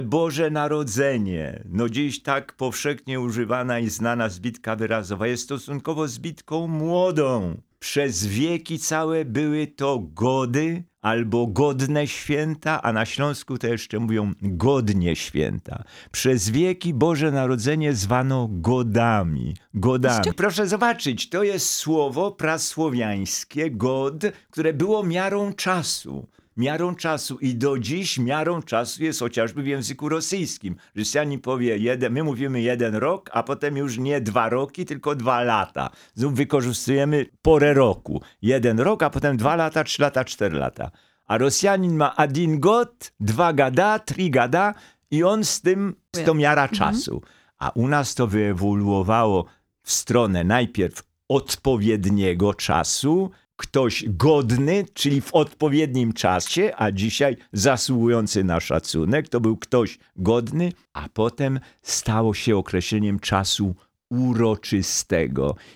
Językoznawca, prof. Jan Miodek odwiedził w pierwszy dzień świąt studio Radia Rodzina.